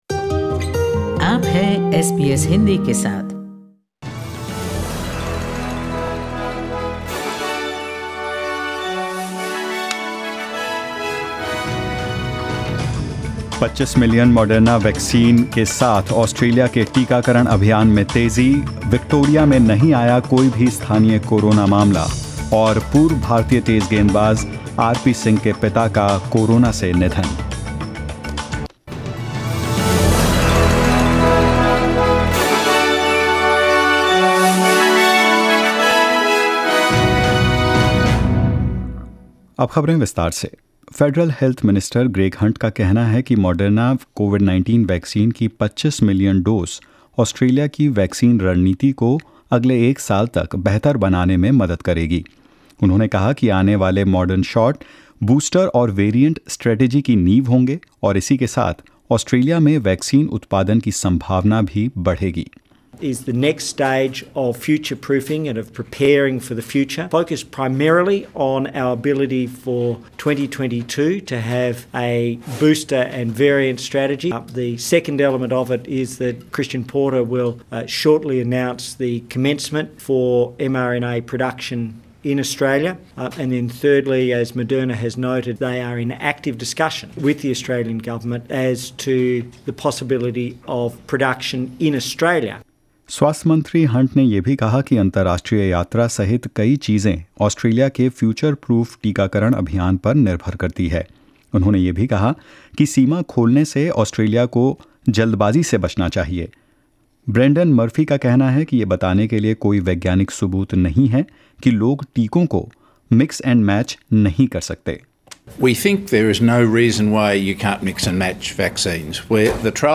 In this latest SBS Hindi News bulletin of India and Australia: No new local cases of COVID-19 detected in Victoria; Delhi runs out of Covaxin stock for 18-44 age group and more.